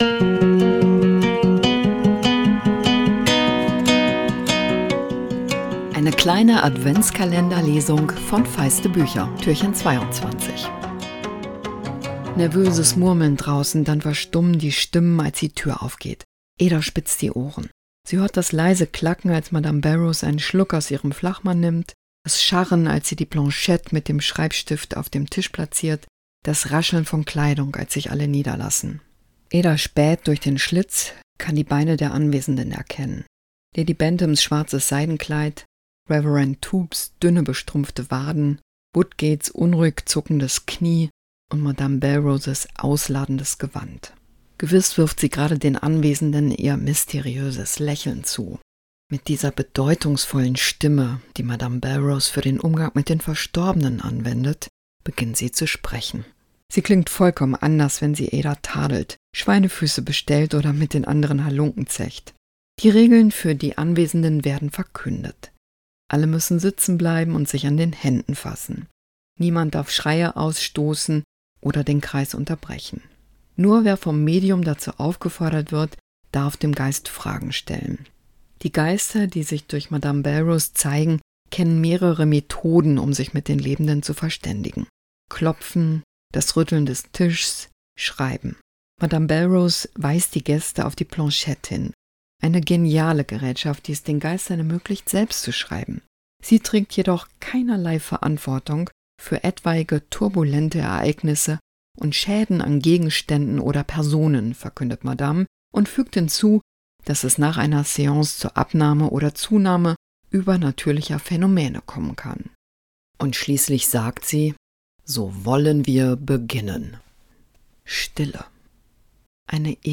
Adventskalender-Lesung 2024! Jess Kidd nimmt euch mit ins Leben des Waisenmädchens Ada Lark. Der Showdown beginnt...